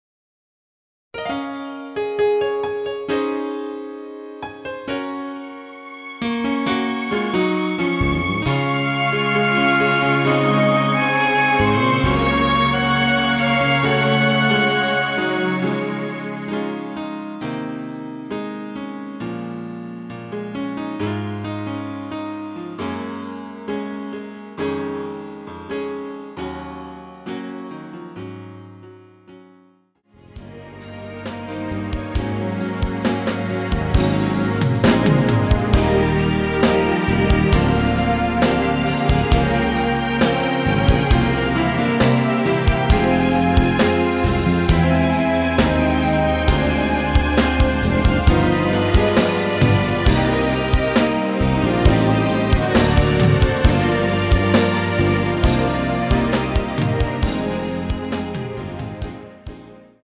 *부담없이즐기는 심플한 MR~
Db
앞부분30초, 뒷부분30초씩 편집해서 올려 드리고 있습니다.
곡명 옆 (-1)은 반음 내림, (+1)은 반음 올림 입니다.